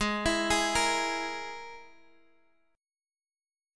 G#sus2#5 chord